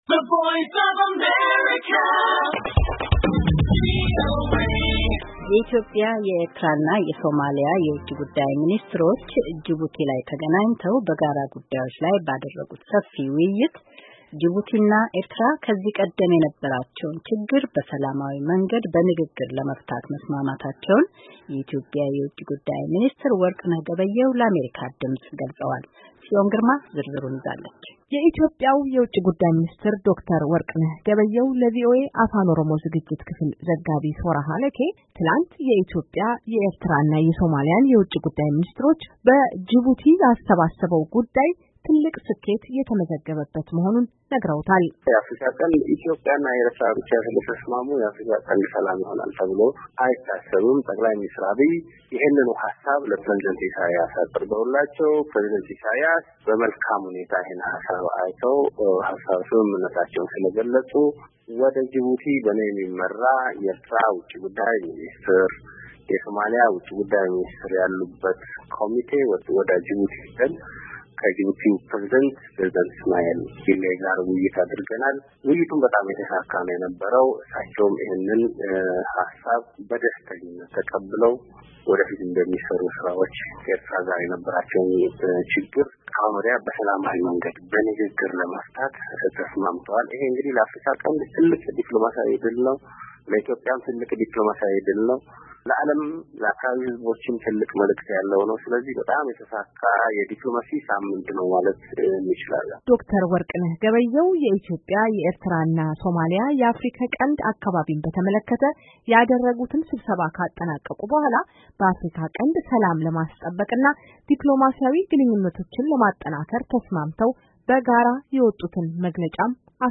የኢትዮጵያ፣ የኤርትራ፣ የሶማሊያና የውጭ ጉዳይ ሚኒስትሮች በጅቡቲ ተገናኝተው በጋራ ጉዳዮች ላይ ባደርጉት ሰፊ ውይይት፤ ጅቡቲና ኤርትራ ከዚህ ቀደም የነበራቸው ችግር በሰላማዊ መንገድ በንግግር ለመፍታት መስማማታቸውን የኢትዮጵያው የውጭ ጉዳይ ሚኒስትር ለአሜሪካ ድምፅ ገለጹ።